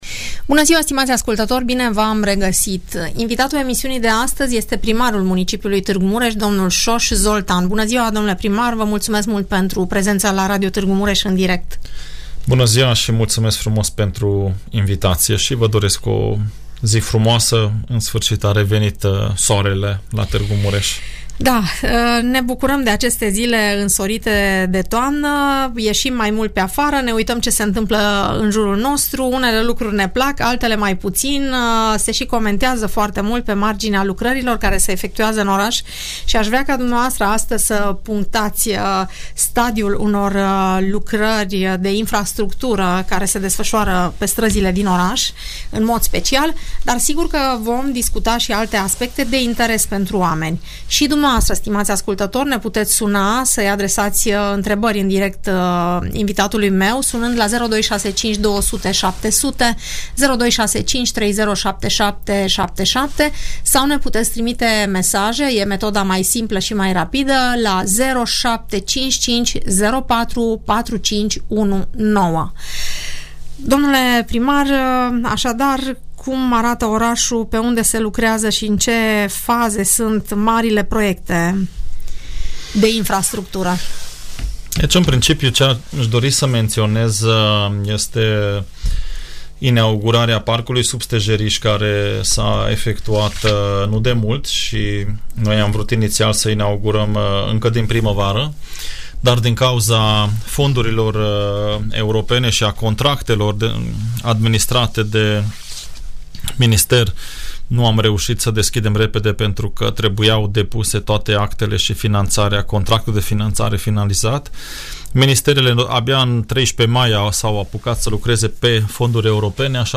Primarul Soós Zoltán, răspunde întrebărilor târgumureșenilor.